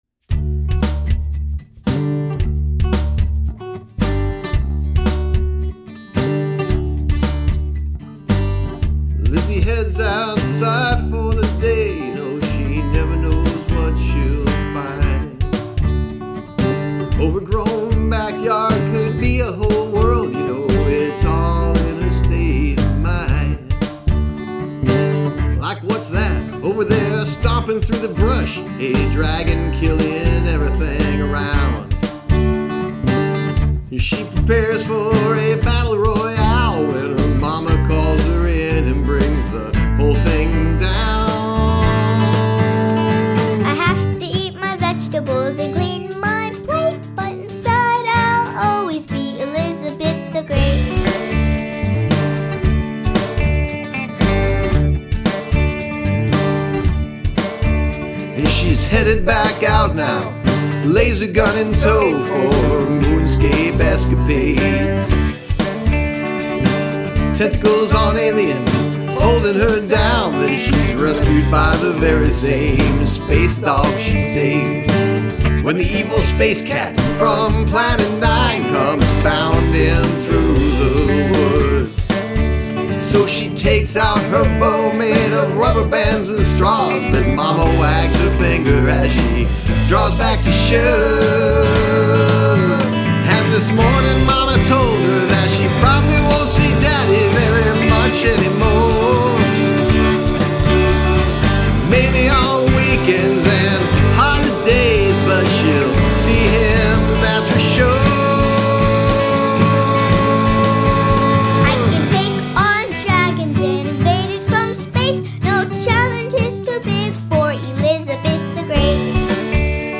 Dramatic Change in Tempo